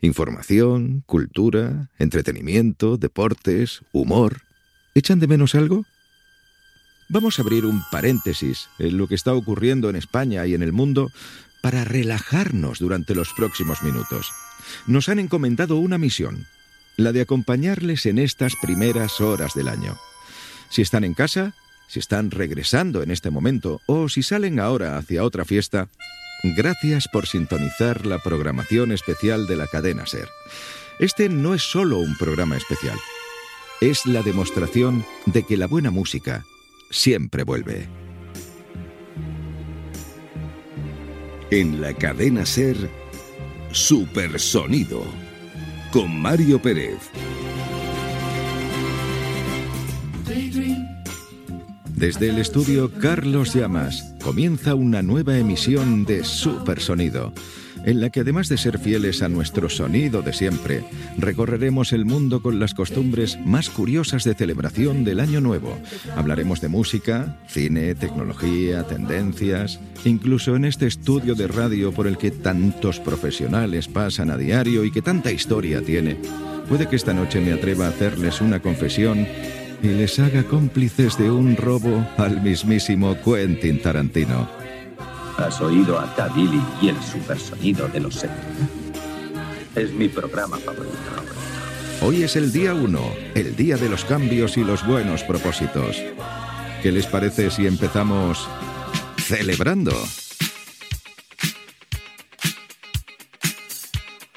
Presentació del programa especial de la nit de cap d'any, indicatiu, continguts que s'hi oferiran i tema musical
Musical